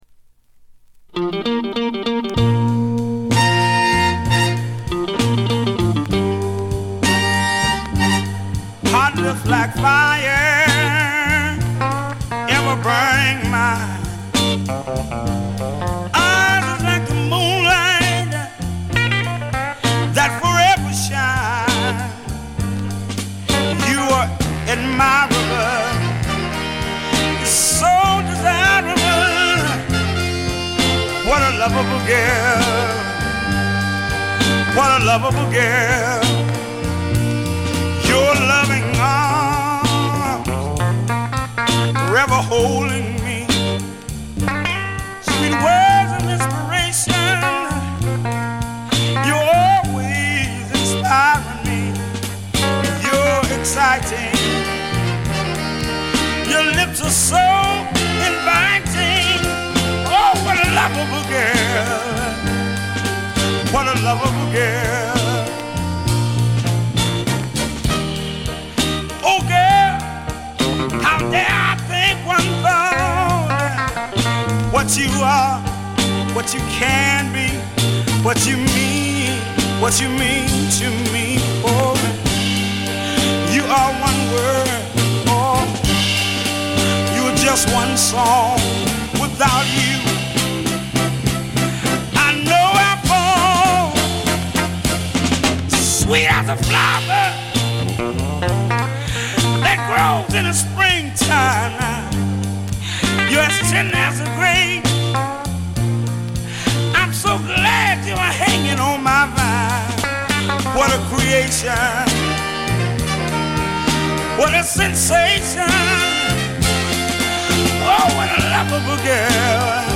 モノラル・プレス。
試聴曲は現品からの取り込み音源です。